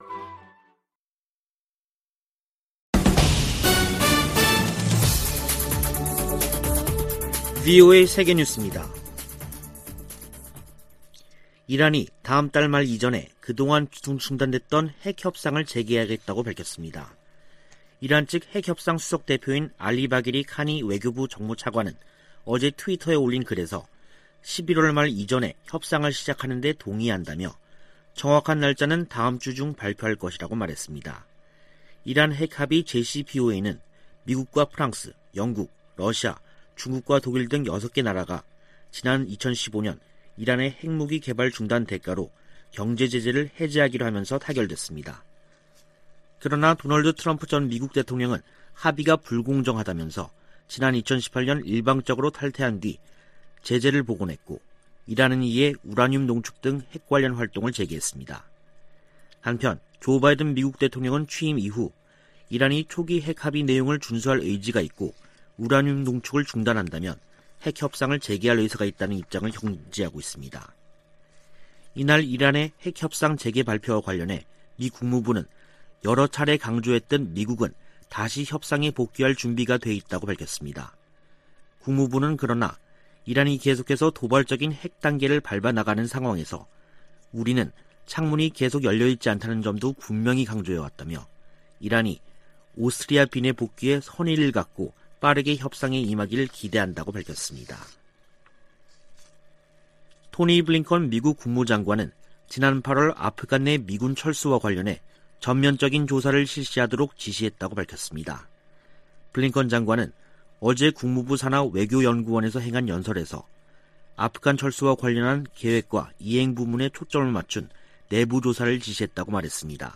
VOA 한국어 간판 뉴스 프로그램 '뉴스 투데이', 2021년 10월 28일 2부 방송입니다. 북한이 종전선언 논의를 위한 선결 조건으로 미-한 연합훈련 중단을 요구하고 있다고 한국 국가정보원이 밝혔습니다. 마크 밀리 미 합참의장은 북한이 미사일 등으로 도발하고 있다며, 면밀히 주시하고 있다고 밝혔습니다. 토니 블링컨 미 국무장관이 보건과 사이버 안보 등에 전문성을 갖추고 다자외교를 강화하는 미국 외교 미래 구상을 밝혔습니다.